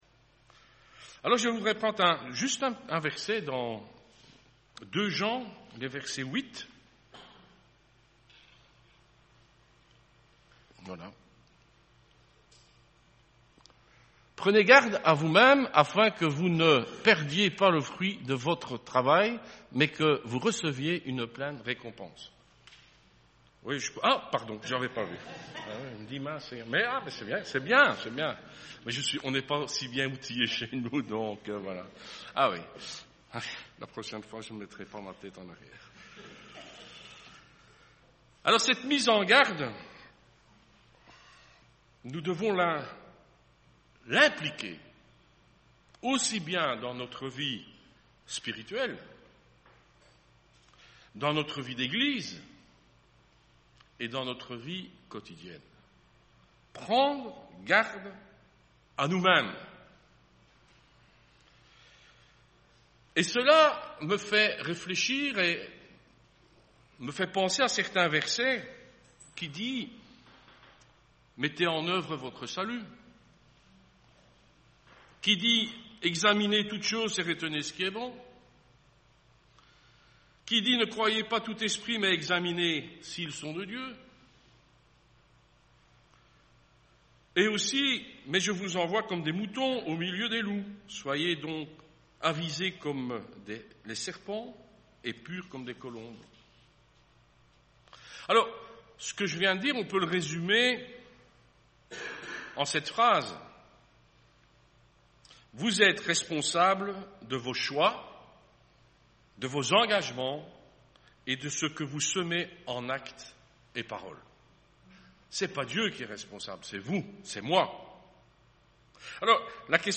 Type De Service: Dimanche matin